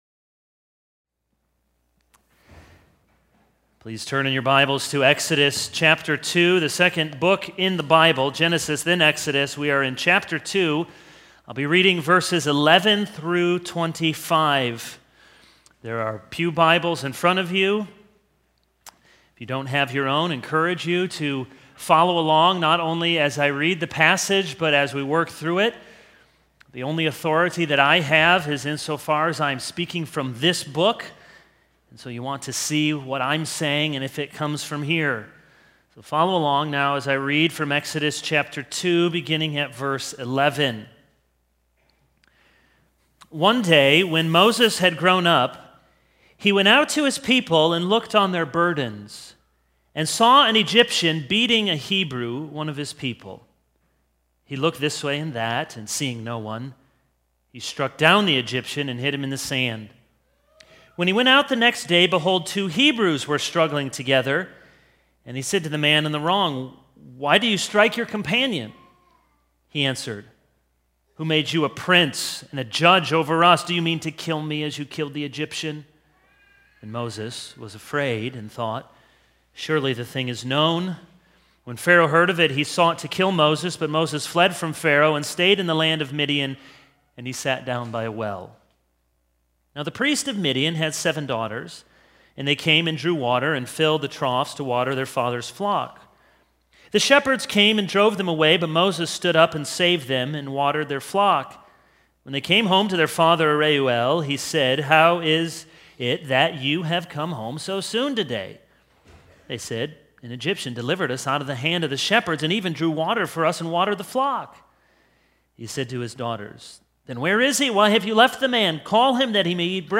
This is a sermon on Exodus 2:11-25.